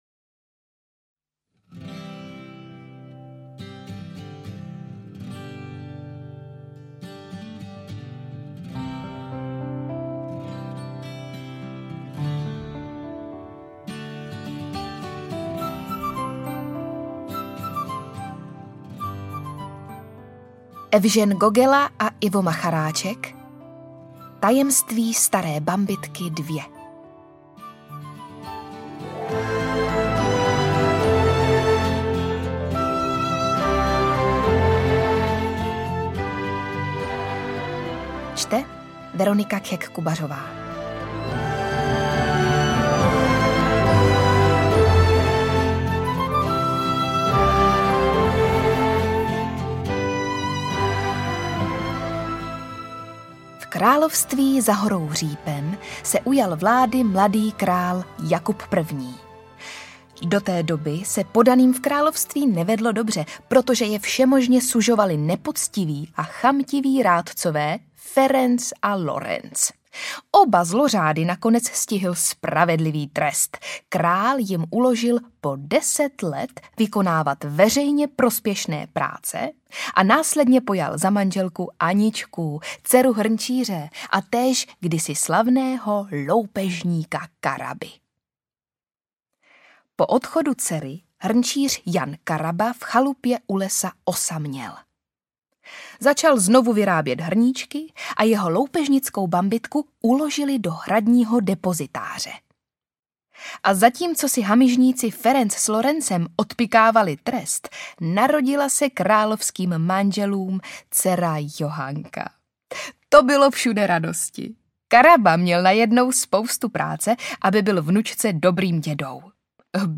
Interpret:  Veronika Khek Kubařová
Vtipný a okouzlující pohádkový příběh umocněný písněmi Václava Noid Bárty a Evžena Gogely.
AudioKniha ke stažení, 18 x mp3, délka 3 hod. 38 min., velikost 198,7 MB, česky